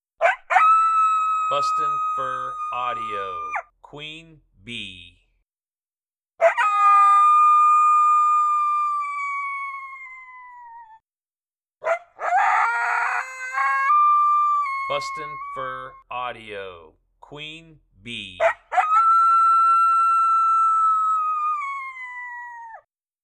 Beans howling as close to a lone howl as she can produce!